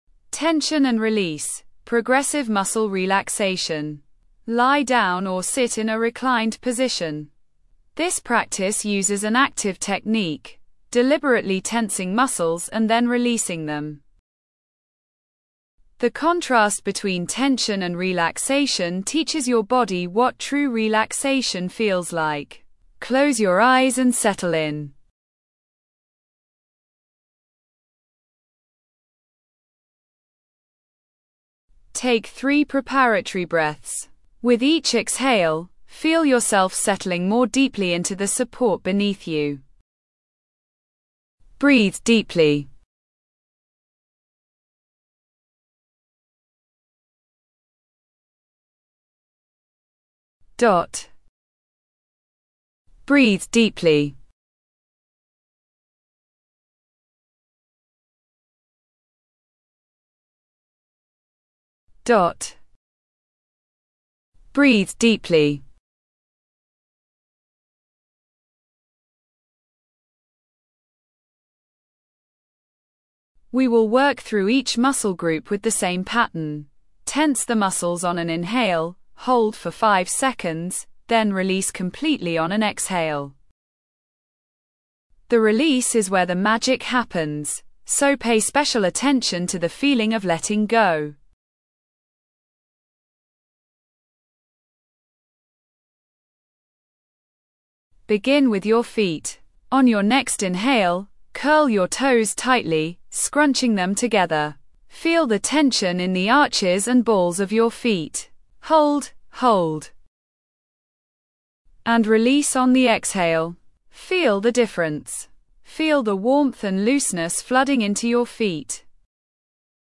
body scan 18 min intermediate
tension-release-progressive-muscle-relaxation.mp3